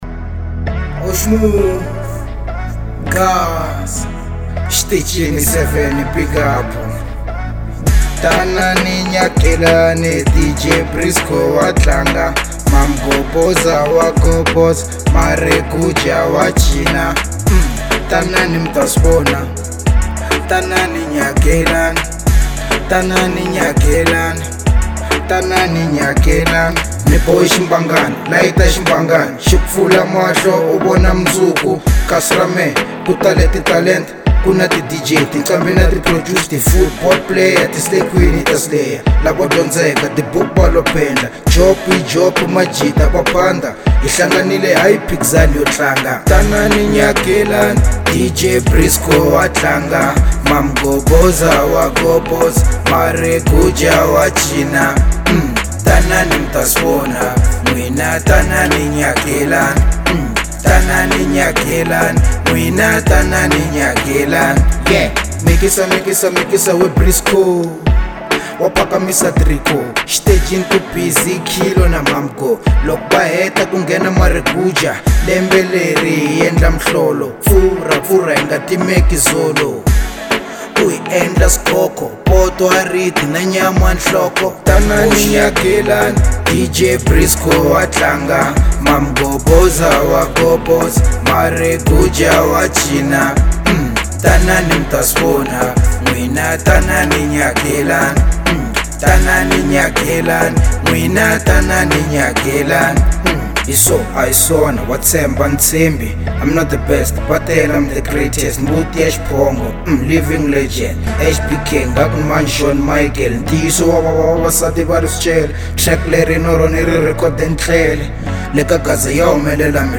03:10 Genre : Afro Pop Size